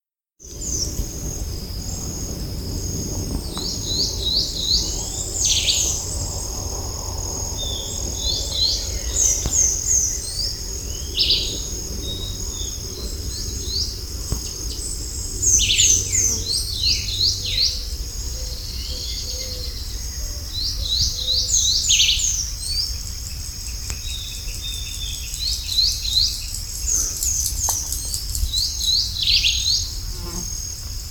Curió (Sporophila angolensis)
Nombre en inglés: Chestnut-bellied Seed Finch
Fase de la vida: Adulto
Localidad o área protegida: Reserva Privada y Ecolodge Surucuá
Condición: Silvestre
Certeza: Vocalización Grabada